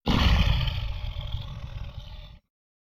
PixelPerfectionCE/assets/minecraft/sounds/mob/guardian/elder_hit2.ogg at mc116
elder_hit2.ogg